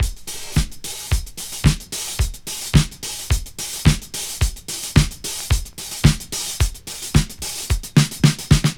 • 110 Bpm Drum Beat D# Key.wav
Free breakbeat - kick tuned to the D# note. Loudest frequency: 1829Hz
110-bpm-drum-beat-d-sharp-key-CmI.wav